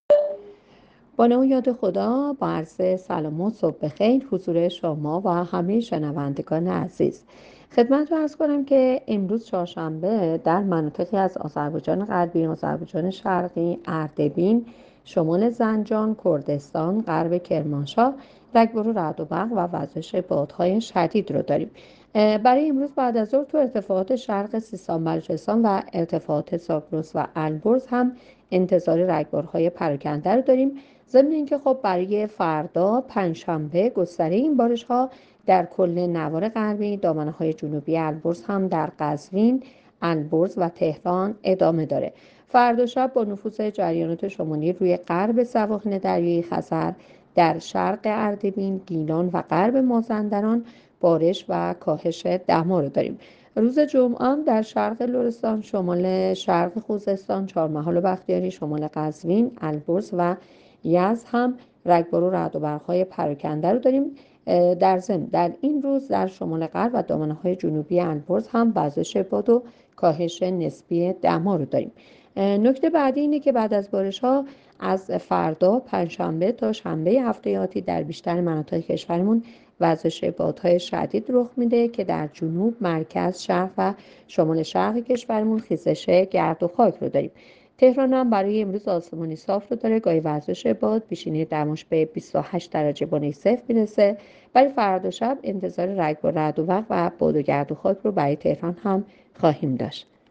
گزارش رادیو اینترنتی پایگاه‌ خبری از آخرین وضعیت آب‌وهوای ۲۰ فروردین؛